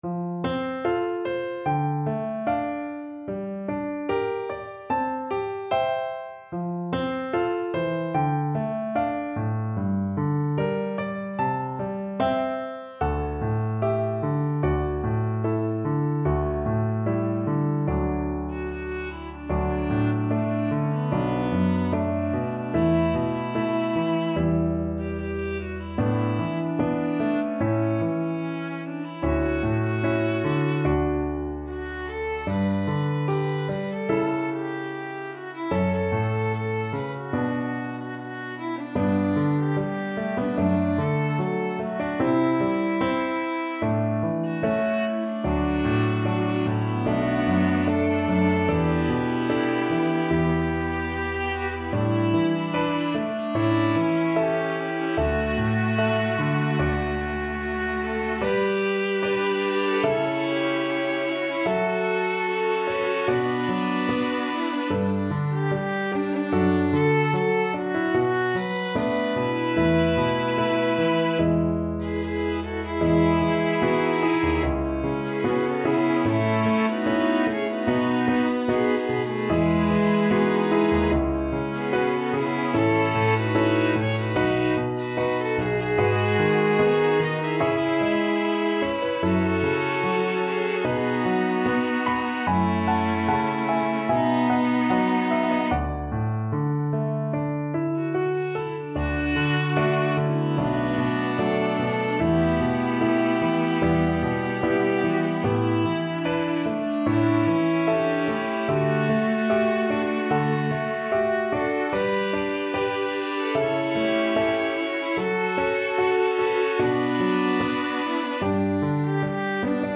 SSAA + piano/band